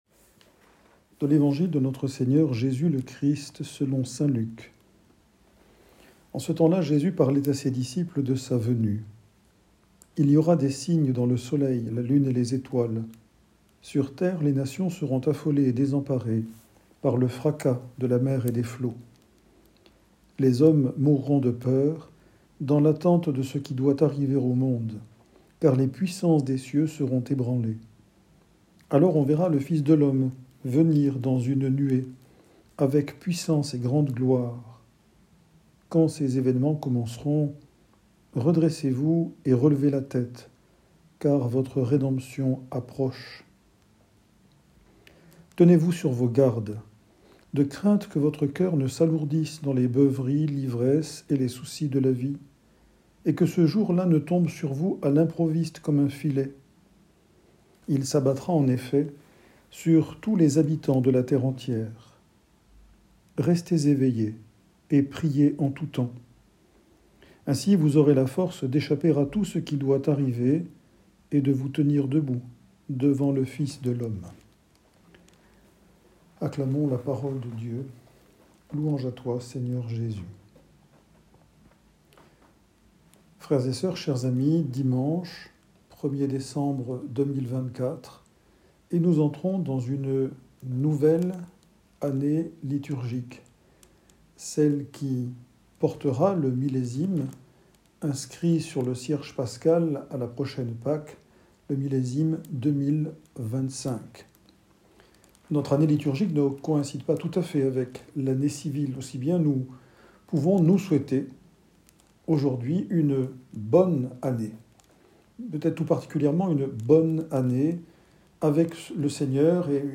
Homélie 1er dimanche de l'Avent Année C 2024